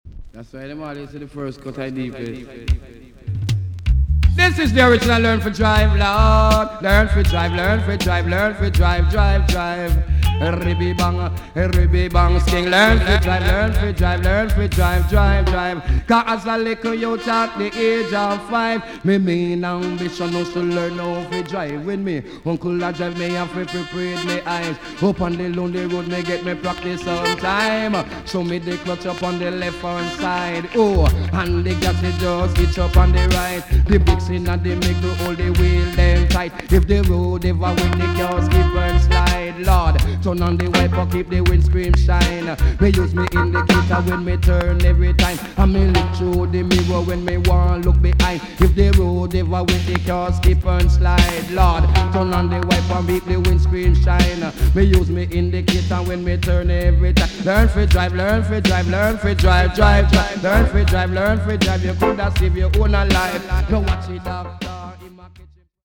TOP >80'S 90'S DANCEHALL
EX- 音はキレイです。
NICE DJ STYLE!!